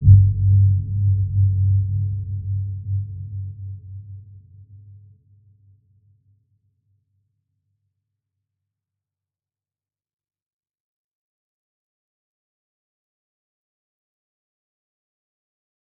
Dark-Soft-Impact-G2-mf.wav